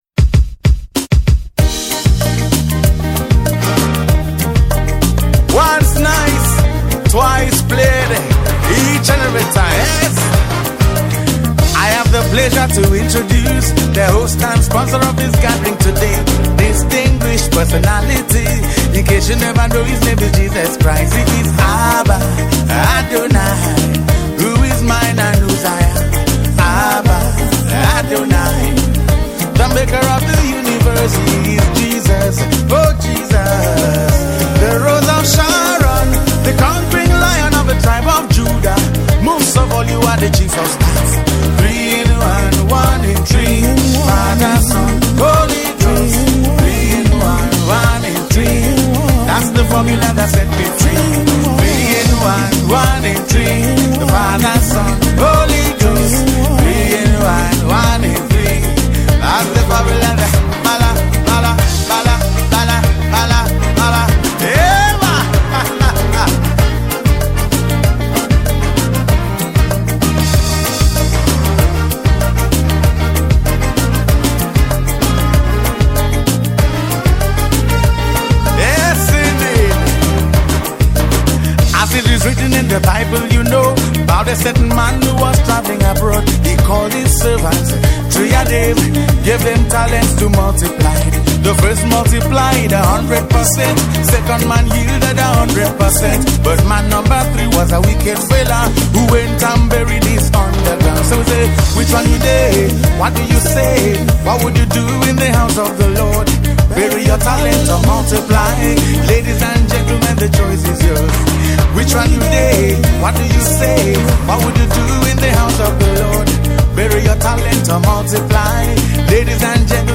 poet spoken words and reggae gospel artist.